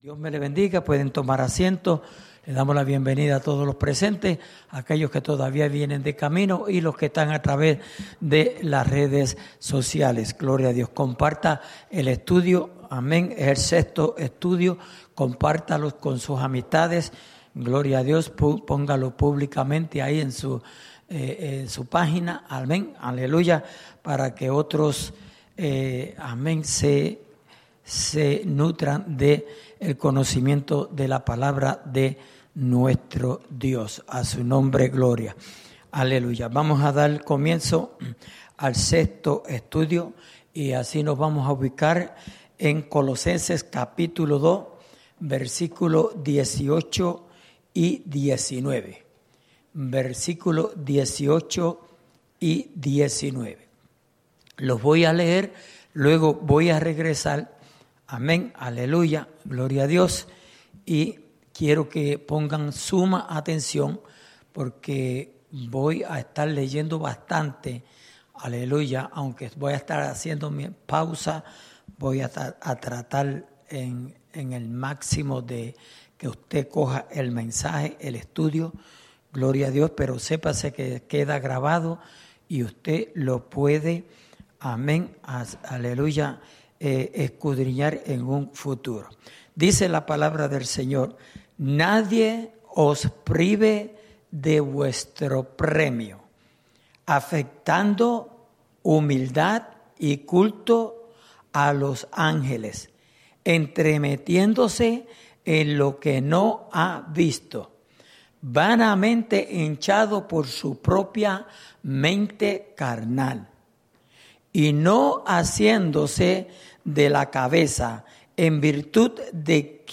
Estudio Bíblico: Libro de Colosenses (Parte Sies)